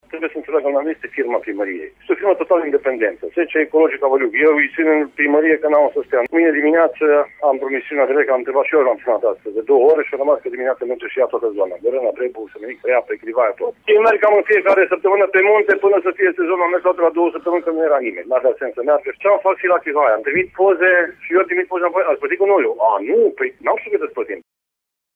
Primar-Sorin-Blaga.mp3